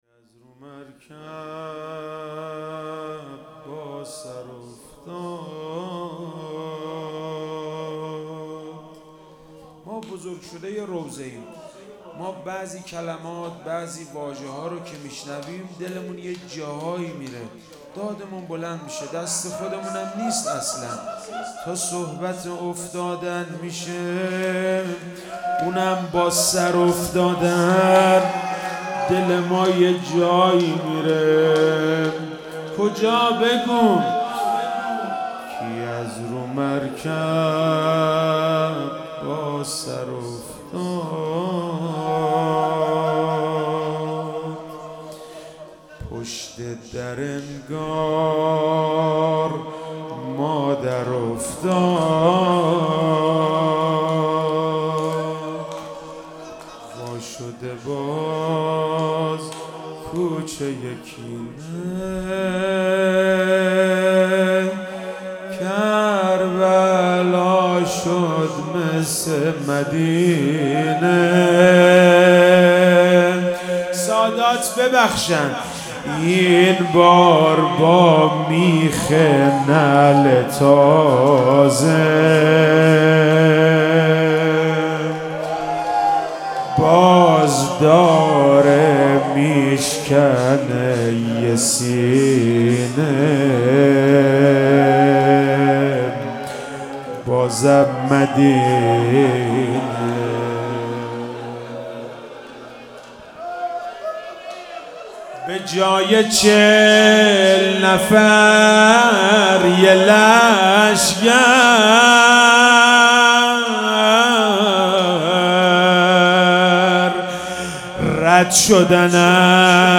محرم99 - شب ششم - روضه - از رو مرکب با سر افتاد